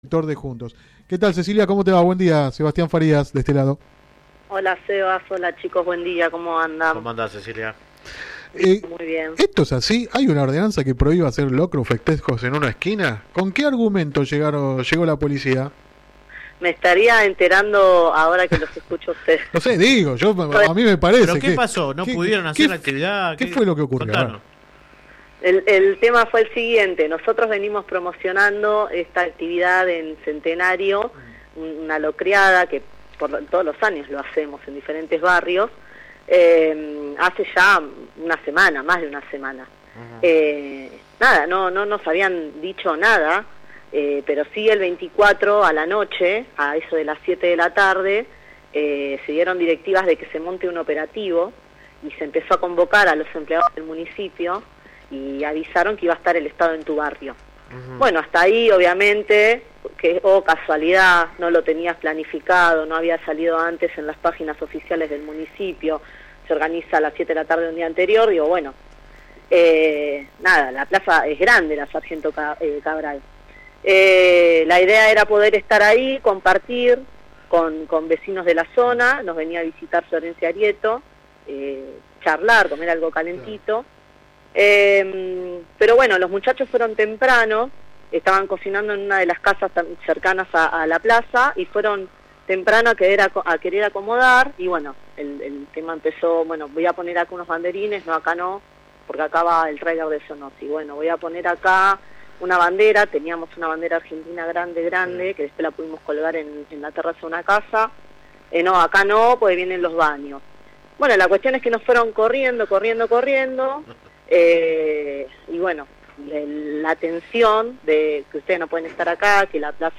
La edil contó en el programa radial Sin Retorno (lunes a viernes de 10 a 13 por GPS El Camino FM 90 .7 y AM 1260) que la “locreada” iba a realizarse en la plaza Sargento Cabral de Villa Centenario.
Click acá entrevista radial